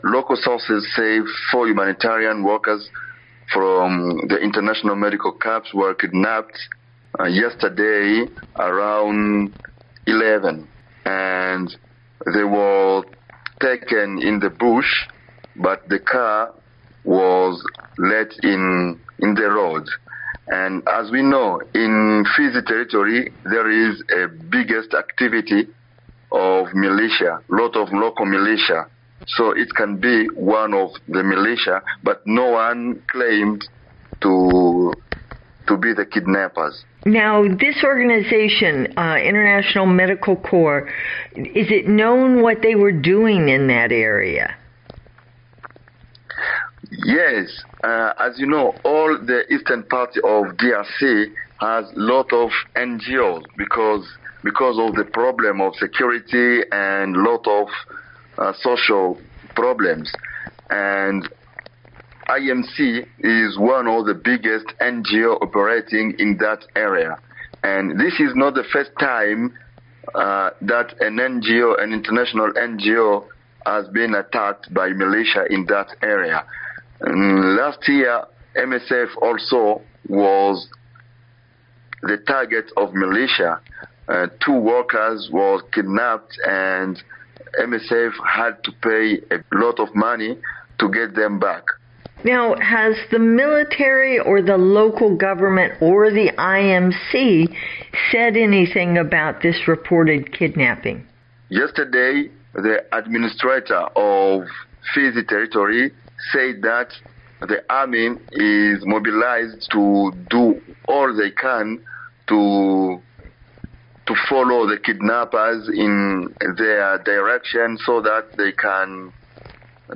spoke to reporter